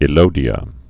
(ĭ-lōdē-ə)